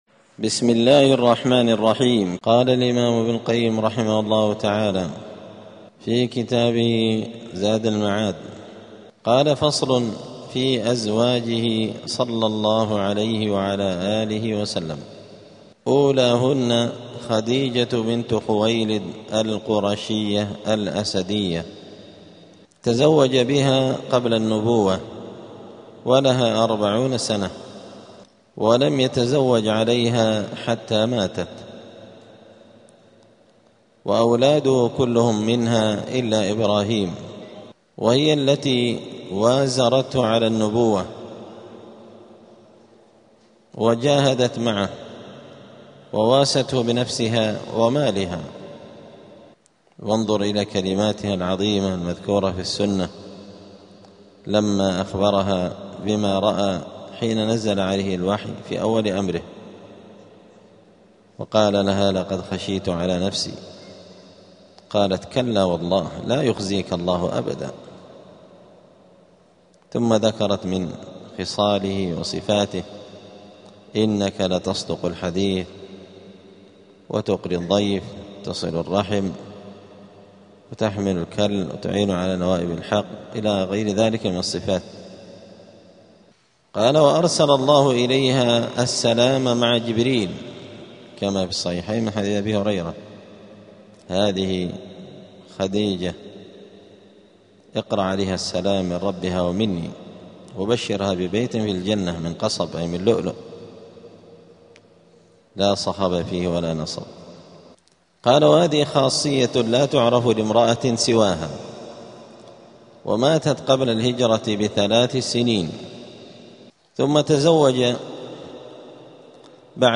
*الدرس الثامن عشر (18) {ﻓﺼﻞ ﻓﻲ أزواجه صلى الله عليه وسلم}.*